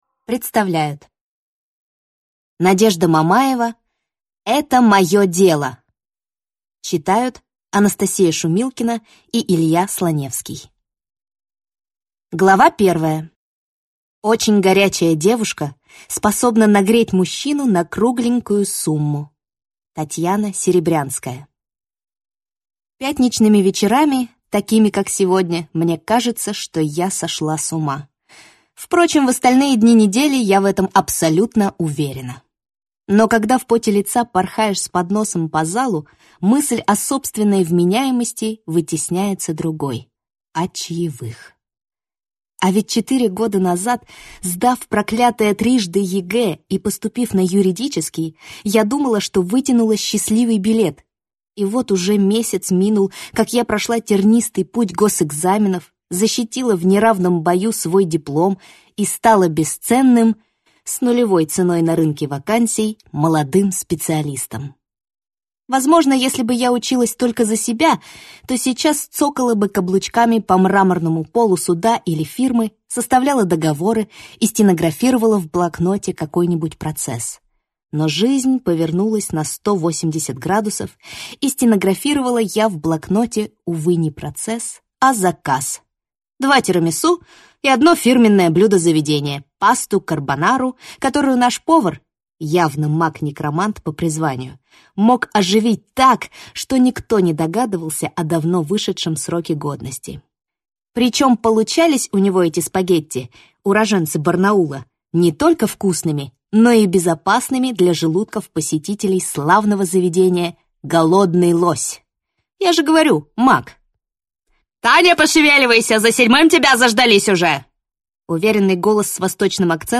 Аудиокнига Это мое дело!